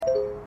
errorWater.mp3